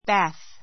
bǽθ バ す ｜ bɑ́ːθ バ ー す